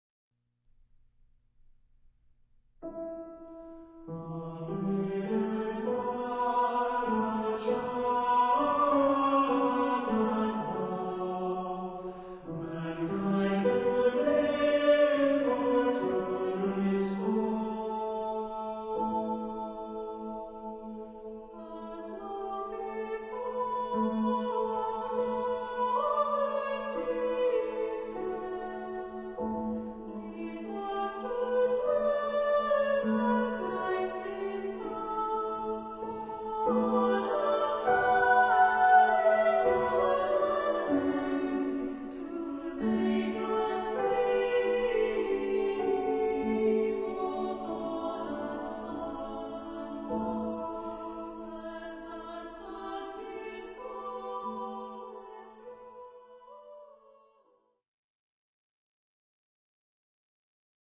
Carol for SATB with piano/harp (ad lib)